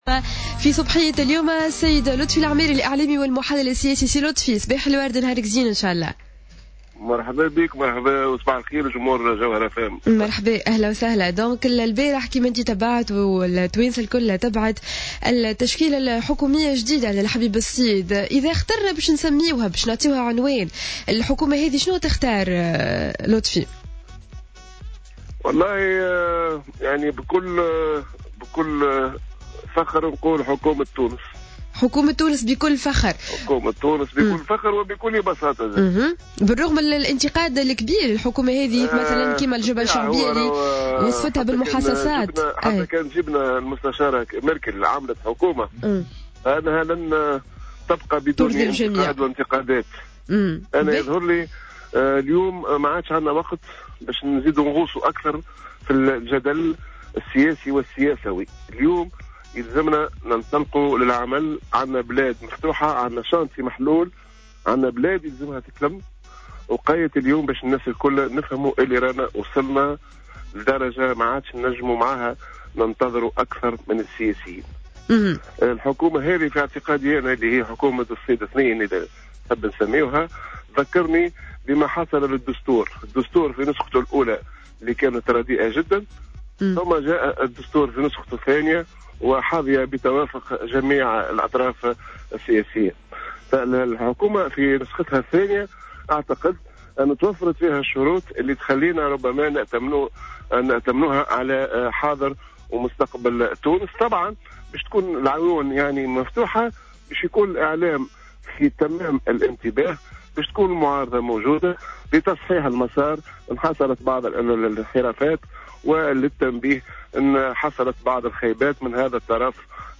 lors de son passage sur les ondes de Jawhara Fm